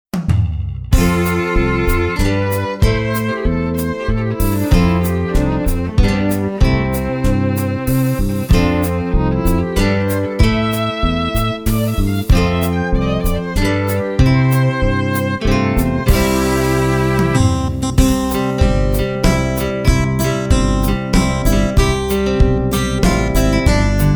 -  Mp3 Mp3 Instrumental Song Track